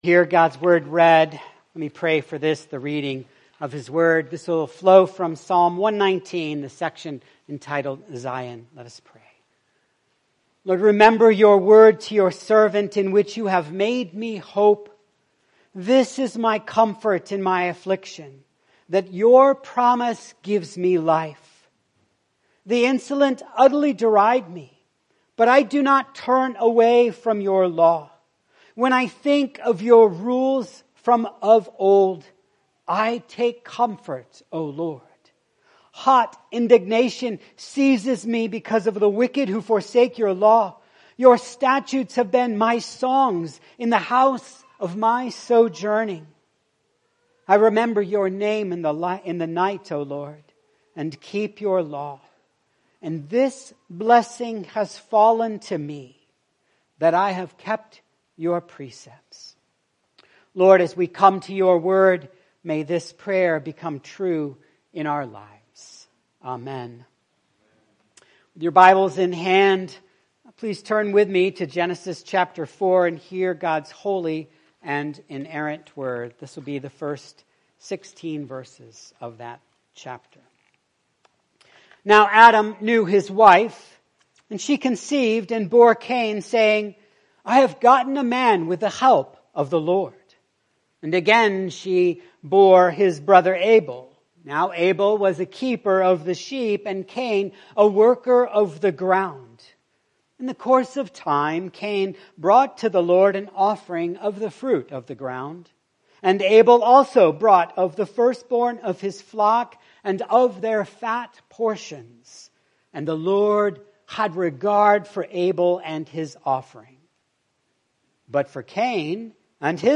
Hear God’s word read.